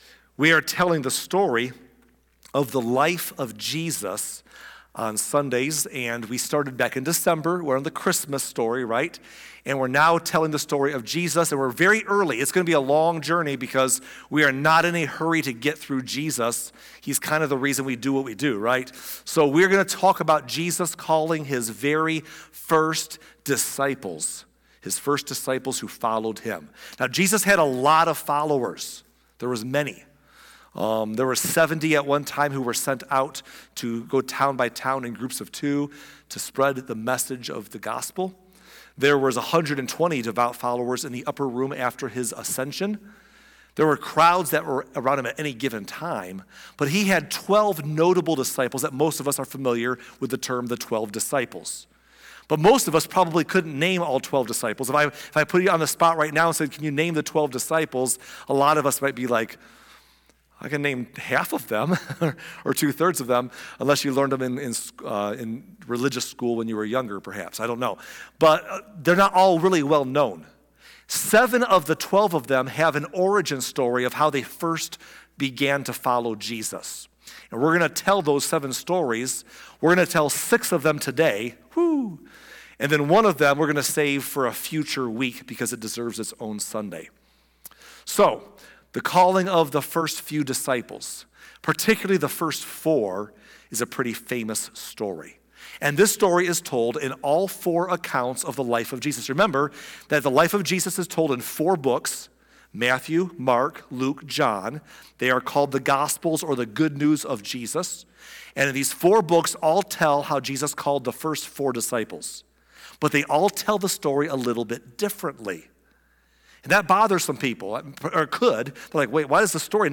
Today's message also included a clip from the series 'The Chosen'.